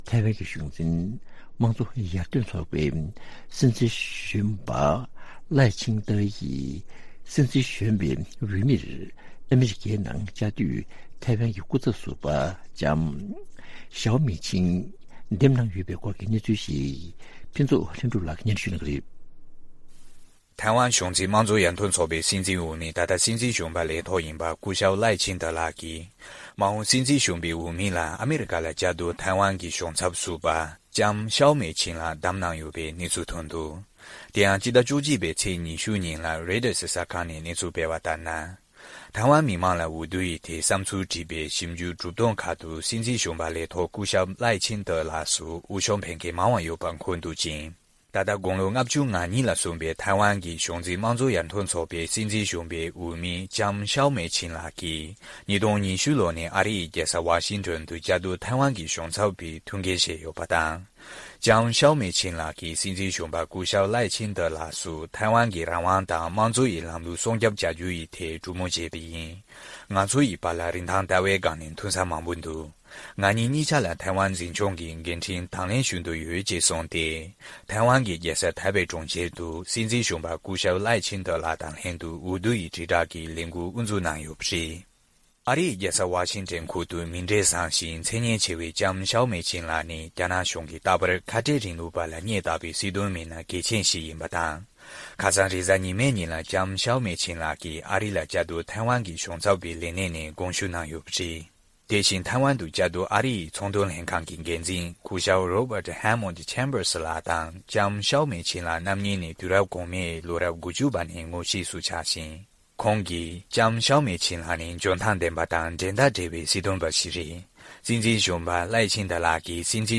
གླེང་མོལ་ཞུ་རྒྱུ།